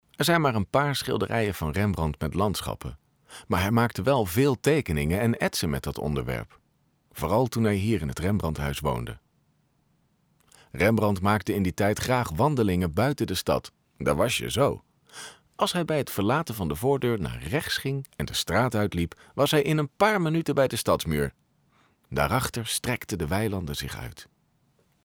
Commerciale, Profonde, Amicale, Chaude, Corporative
Guide audio